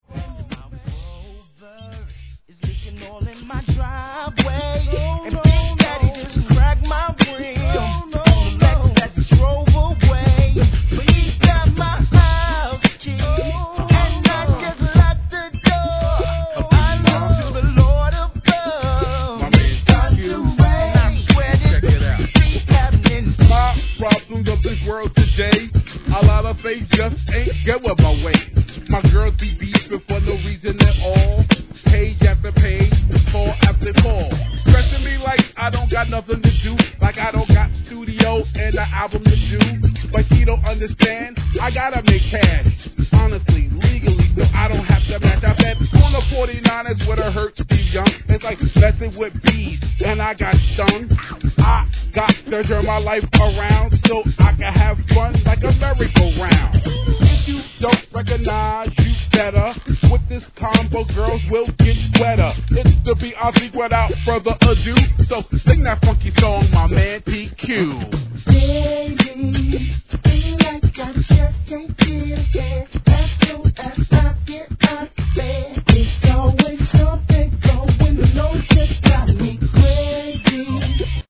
G-RAP/WEST COAST/SOUTH